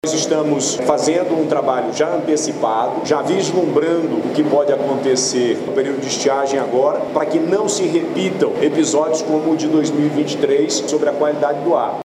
Na ocasião, o Governador Wilson Lima, destacou a organização que estão realizando para a o período de estiagem, em especial sobre a qualidade do ar.
SONORA-WILSON-LIMA.mp3